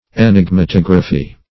Search Result for " enigmatography" : The Collaborative International Dictionary of English v.0.48: Enigmatography \E*nig`ma*tog"ra*phy\, Enigmatology \E*nig`ma*tol"o*gy\, n. [Gr.